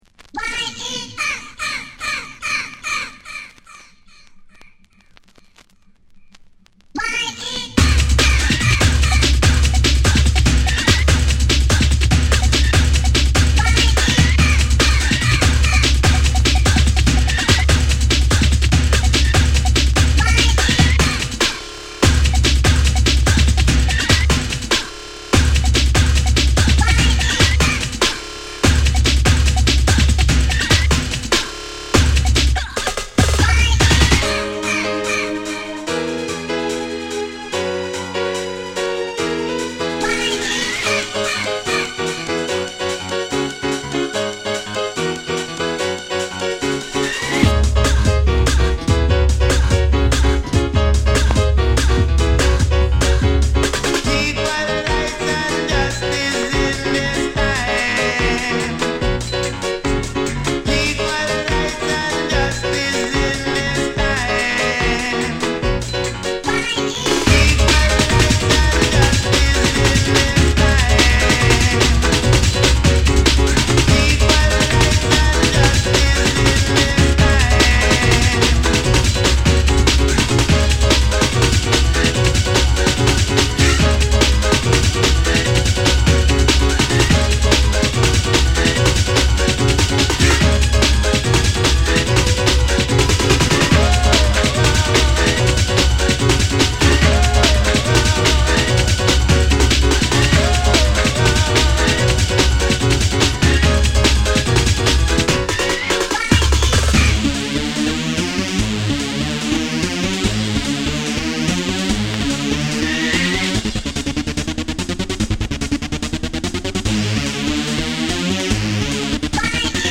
レイヴテクノ・クラシック
これぞピアノが弾けるという形容がぴったりのテクノ・クラシック。